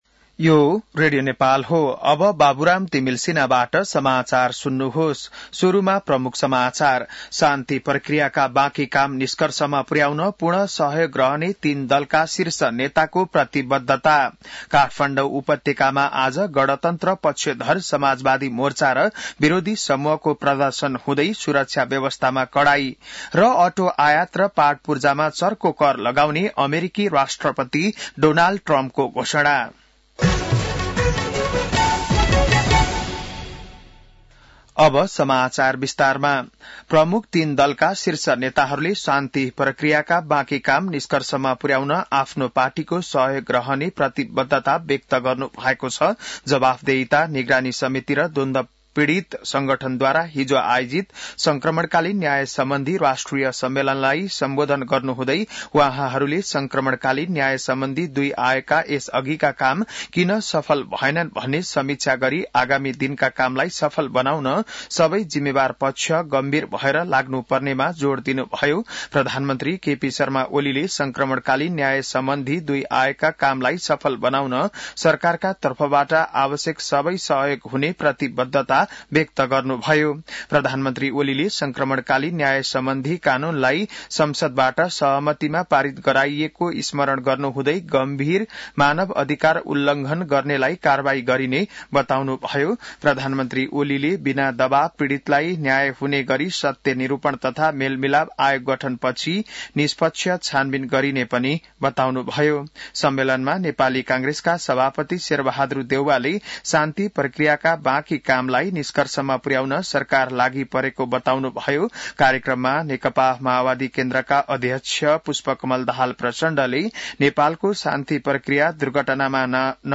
An online outlet of Nepal's national radio broadcaster
बिहान ९ बजेको नेपाली समाचार : १५ चैत , २०८१